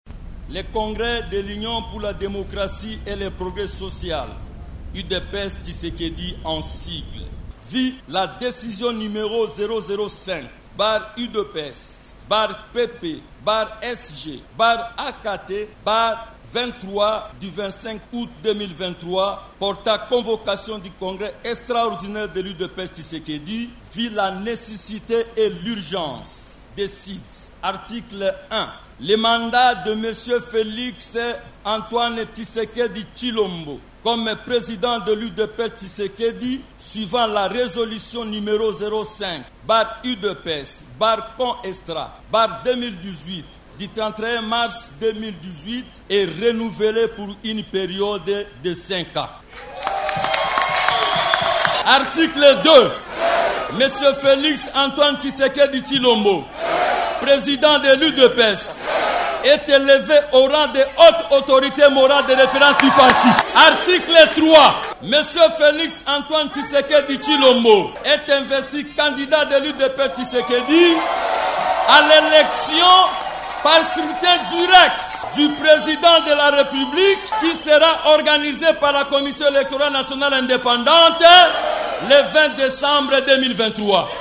Cette désignation est intervenue lors du congrès extraordinaire de ce parti politique organisé à Kinshasa.